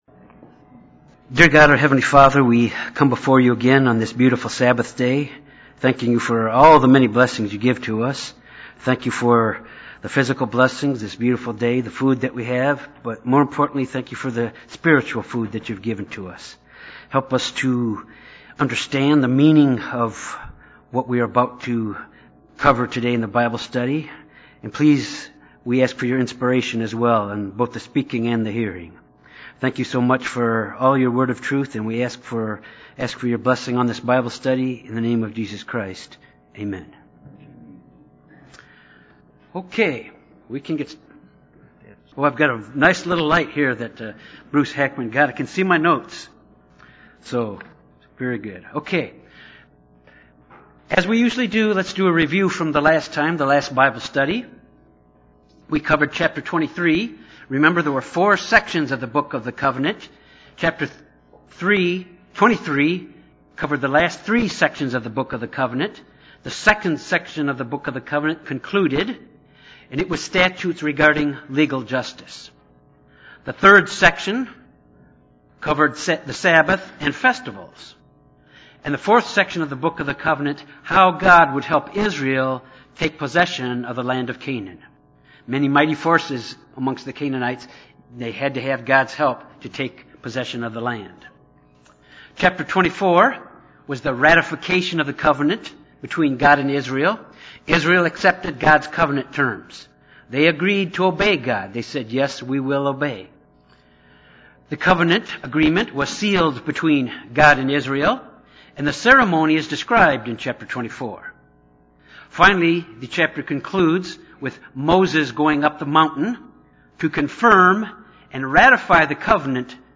This Bible Study focuses on the pattern of the tabernacle and its furnishings within.